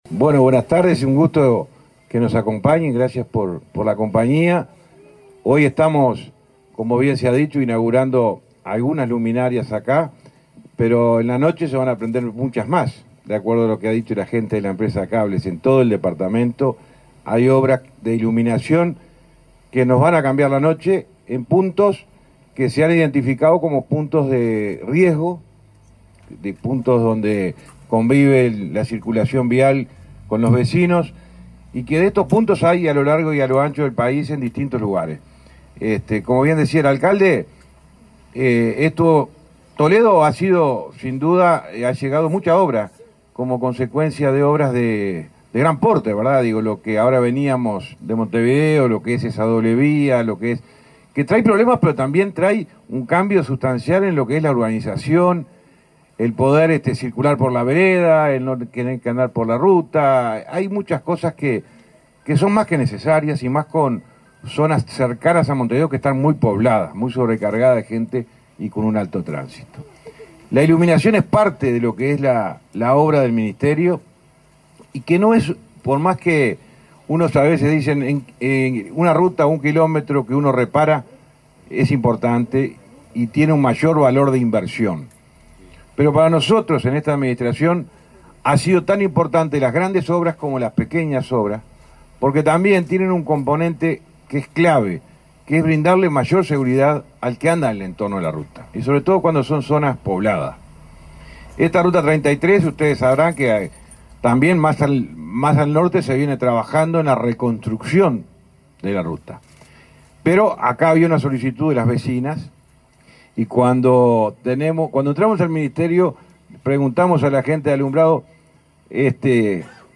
Palabras del ministro de Transporte, José Luis Falero
El ministro de Transporte, José Luis Falero, participó, el martes 26, en la inauguración de luminarias instaladas por esa cartera en Toledo,